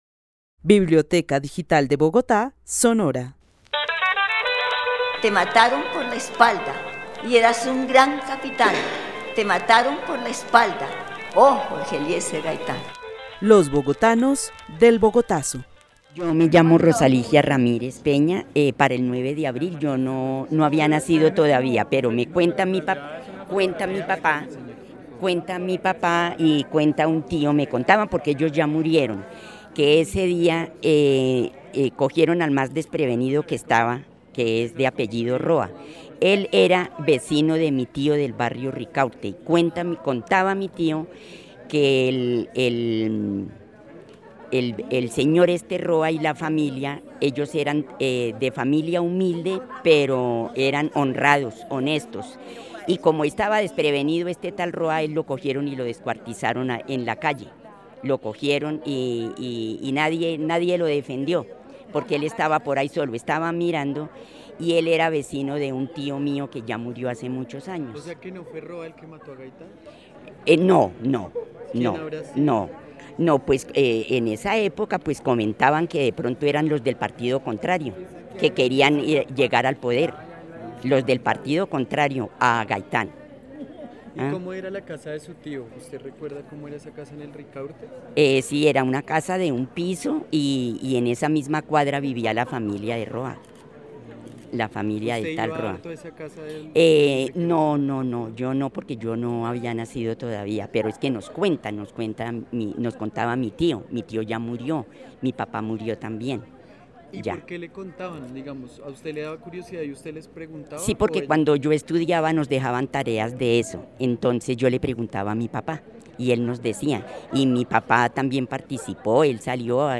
Narración oral de los hechos sucedidos en Bogotá el 9 de abril de 1948, luego del asesinato de Jorge Eliécer Gaitán.
El testimonio fue grabado en el marco de la actividad "Los bogotanos del Bogotazo" con el club de adultos mayores de la Biblioteca El Tunal.